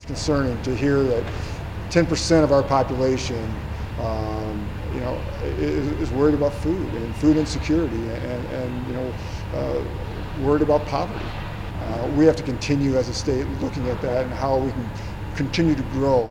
FEENSTRA, WHO IS RUNNING FOR GOVERNOR, SPOKE TO REPORTERS MONDAY AFTER A TOUR OF THE FOOD BANK OF IOWA, WHICH SERVES 700 ORGANIZATIONS IN 55 IOWA COUNTIES.